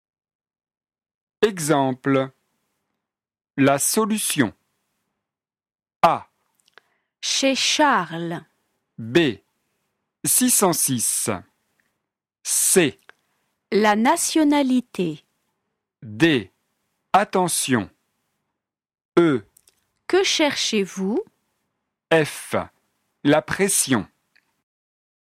• Leçon de phonétique et exercice de prononciation
• Opposition de la consonne [s]  comme dans « essentiel » et [ch] comme dans « cherche ».
2. EXERCICE : Indiquez si vous entendez [s] ou [ch]